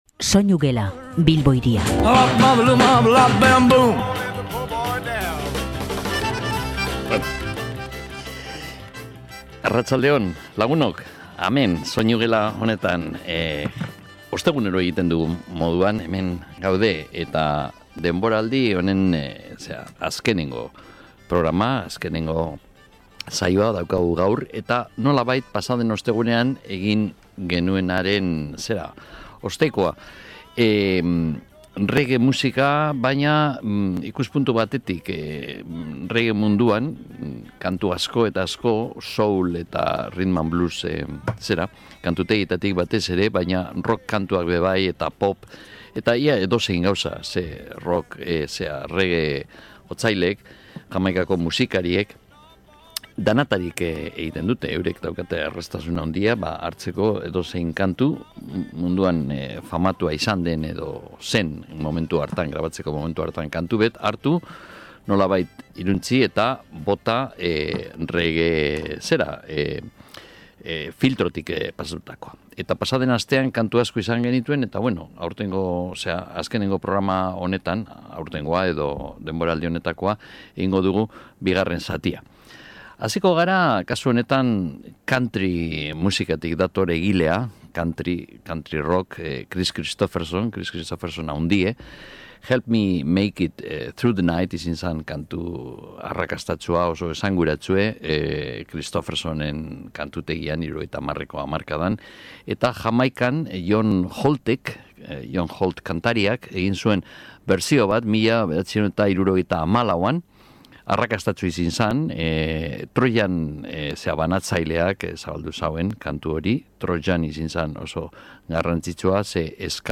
SOINUGELA: Reggae, soul, jazz eta rock musikak